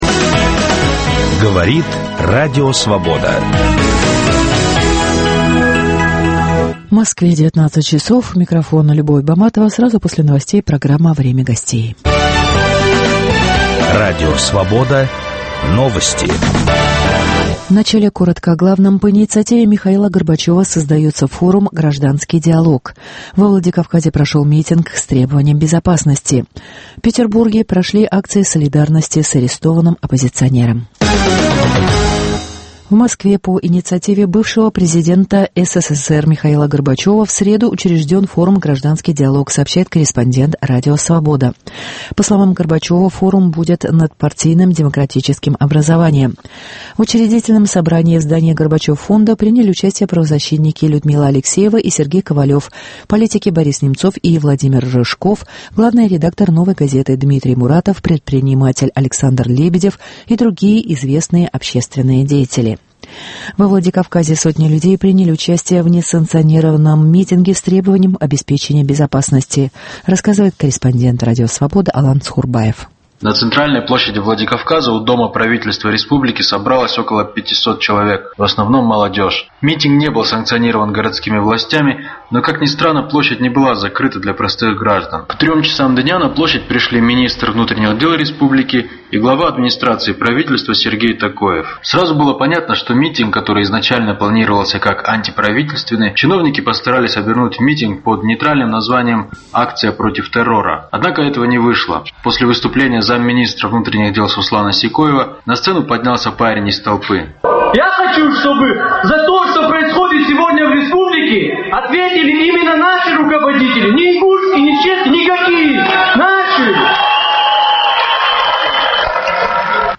Николай Досталь закончил съемки натуры 16-серийного фильма "Раскол". О народе, истории, власти и раскольниках с кинорежиссером беседует Анна Качкаева.